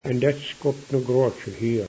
Pronunciation